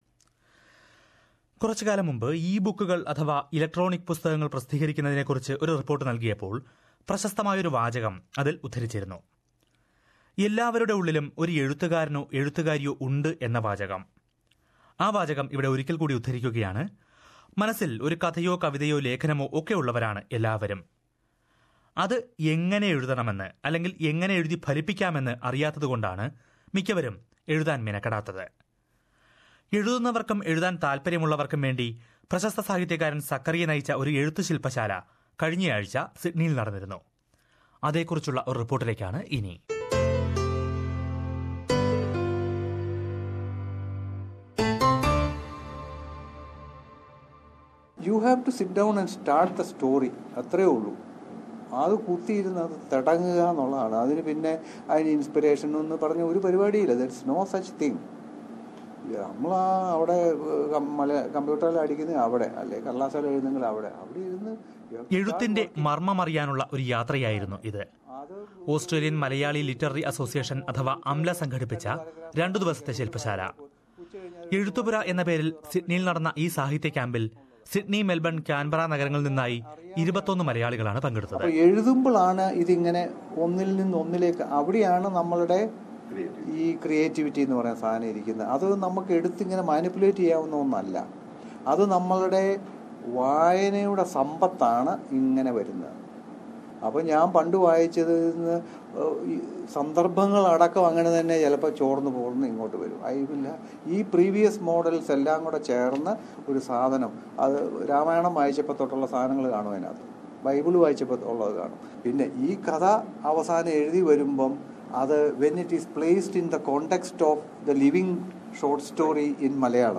New and aspiring Malayalee writers in various Australian cities got together in Sydney last week, to acquire new tricks and techniques from famous novelist Zacharia. Ezhuthupura, a two-day long writing workshop organised by Australian Malayalee Literary Association (AMLA), was a venue for literary lovers to get together. Listen to a report about the workshop.